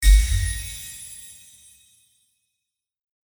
attack_ui_end.mp3